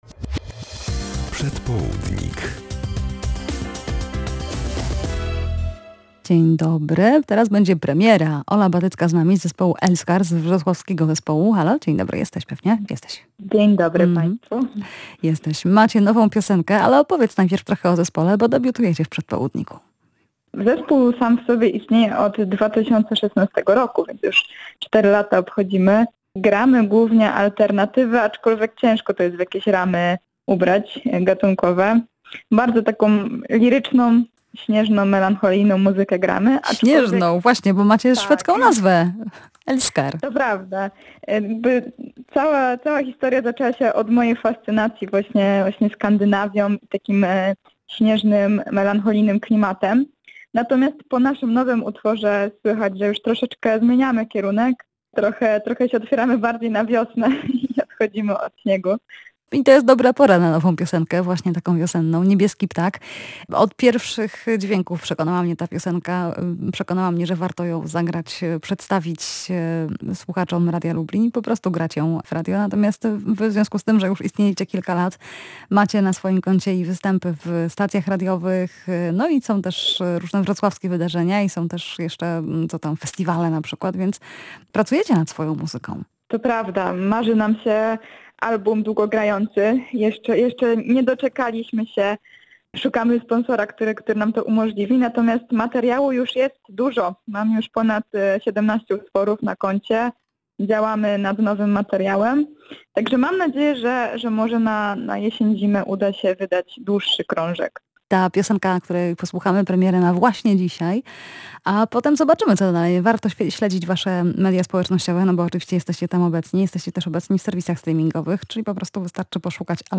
Wiosna, otwarte okno i śpiewające ptaki – to była inspiracja do napisania tej piosenki. Zapraszamy do posłuchania rozmowy